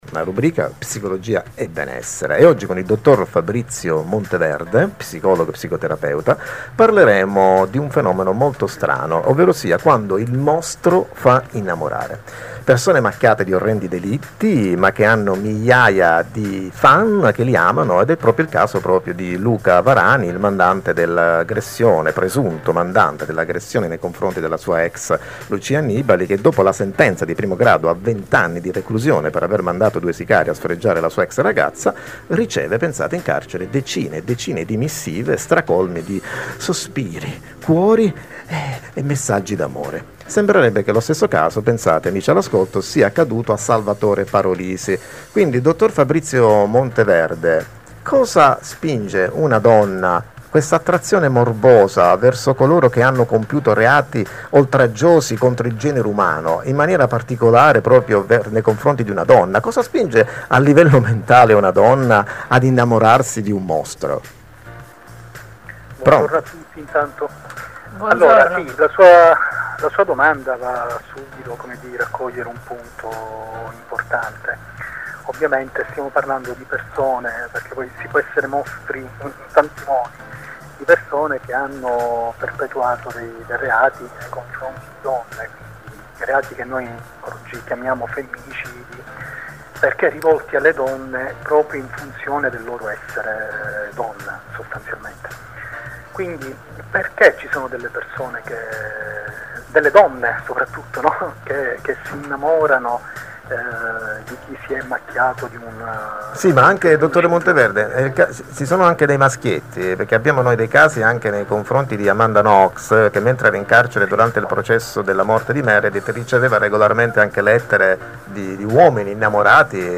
Il Femminicidio: intervista radiofonica